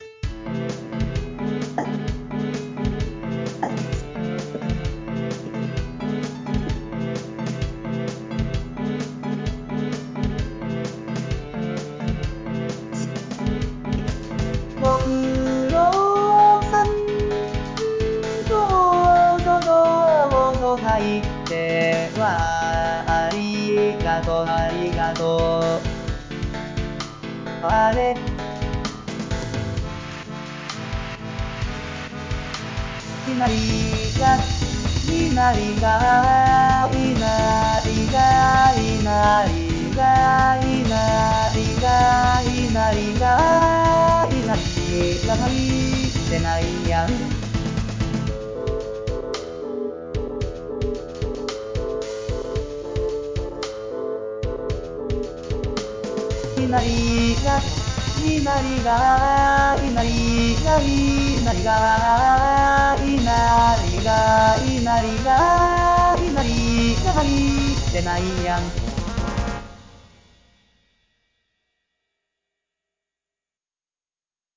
ロンド